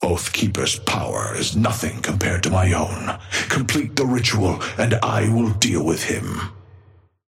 Amber Hand voice line - Oathkeeper's power is nothing compared to my own.
Patron_male_ally_ghost_start_05.mp3